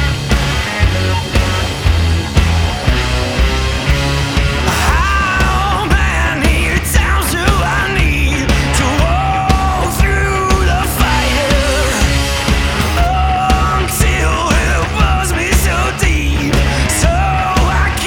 Blues › BluesRock